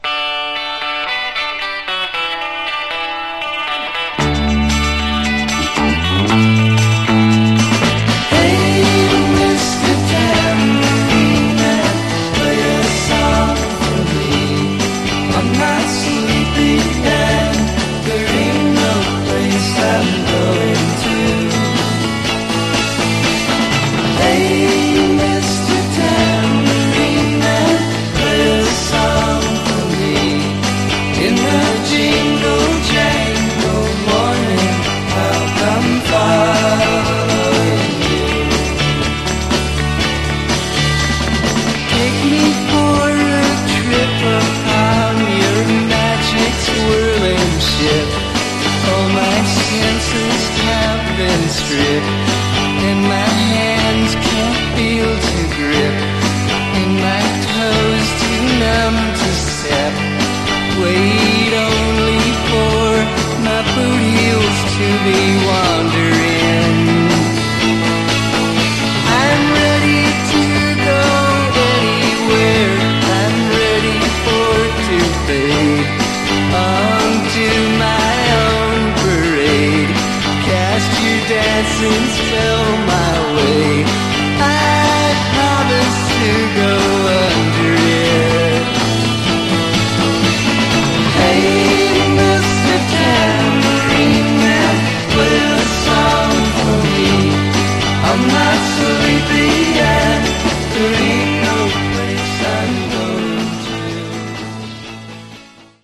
Genre: Folk Rock
The audio will blow you away — brilliant and pristine!